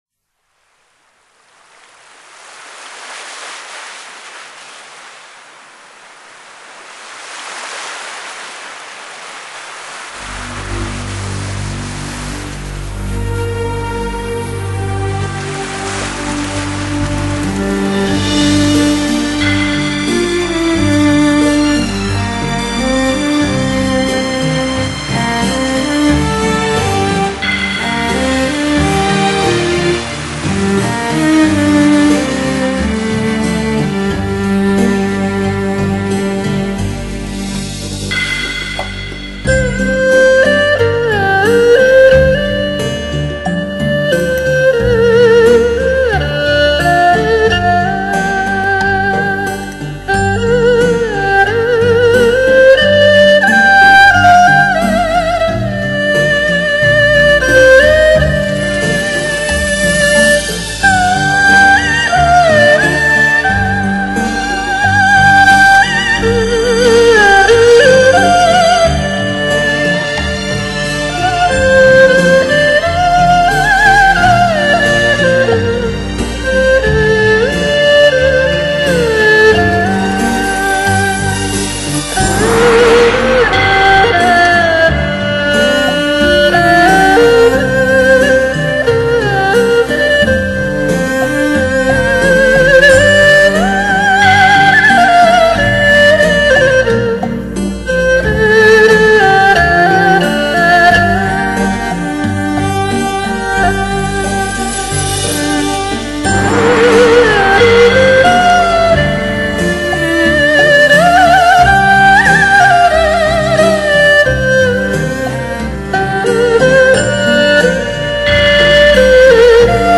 [2007-6-1][二胡与和声]激昂 慷慨 悲愤的二胡演奏 铁血《满江红》 激动社区，陪你一起慢慢变老！